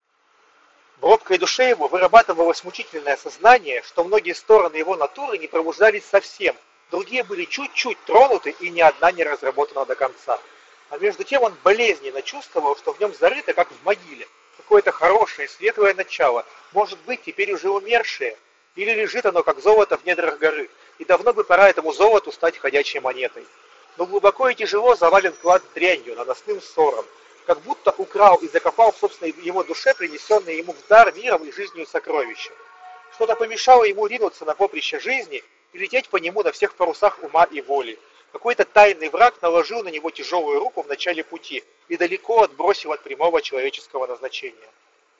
Чтобы оценить ее эффективность, мы вновь запишем два тестовых фрагмента, но на этот раз добавим фоновый шум: включим в расположенной недалеко от места записи акустике звук оживленной улицы и посмотрим, как это отразится на разборчивости речи.
Запись с микрофона гарнитуры (в шумной обстановке)
С фоновым шумом система справляется уверенно, хотя легкий гул все же остается. Иногда в запись попадают высокочастотные звуки вроде гудков машин, скрипов и ударов.
Звучание голоса остается прежним, без заметных искажений или провалов, и это уже неплохо
3-Mic-Noise.mp3